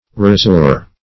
razure.mp3